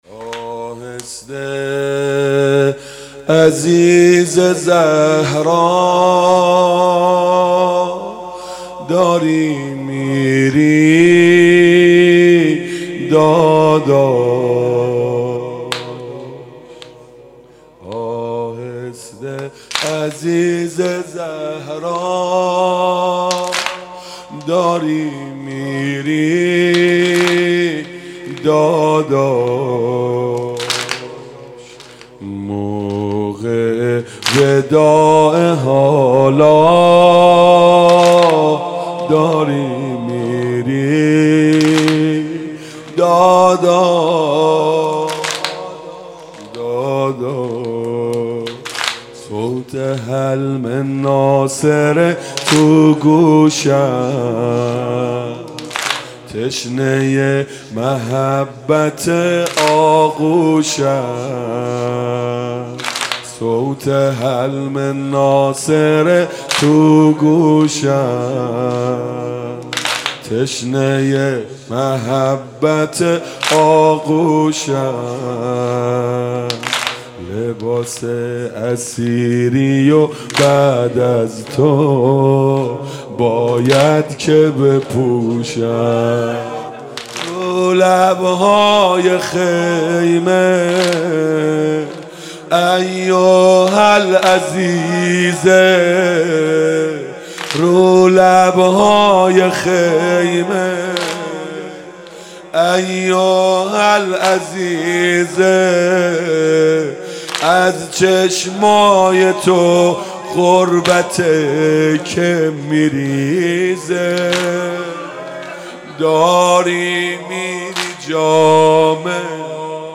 شب عاشورا محرم 97 - واحد - آهسته عزیز زهرا